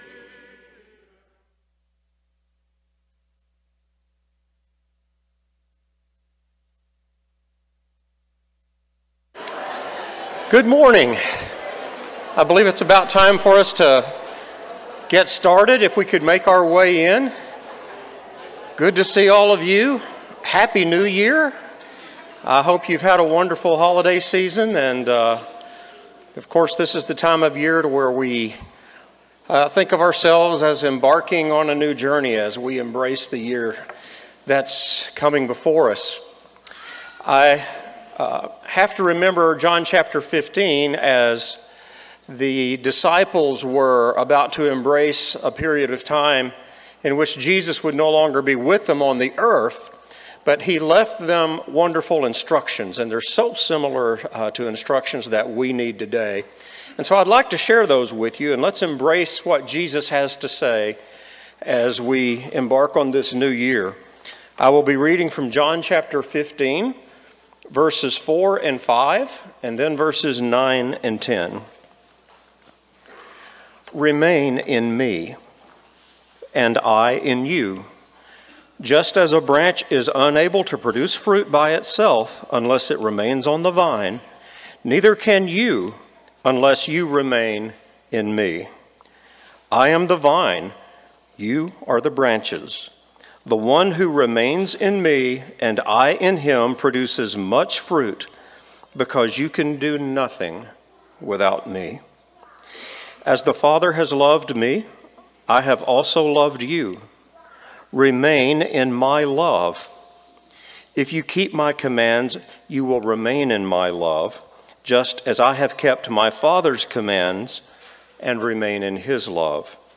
Isaiah 8:20, English Standard Version Series: Sunday AM Service